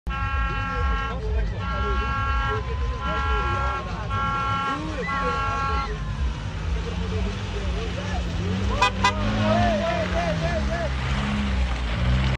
The Legendary Landrover 109 “Muruthi” sound effects free download
The Legendary Landrover 109 “Muruthi” reminded us why it’s a classic sliding, splashing, and stamping its authority with a proper Mud Dance all the way through the Finish Line.